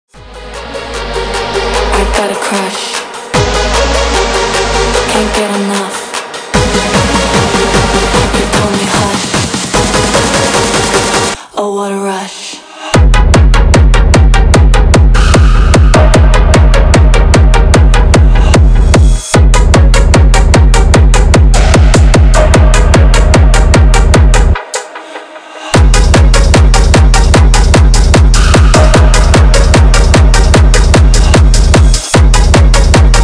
techno, EDM, крутые, подвижные, 2024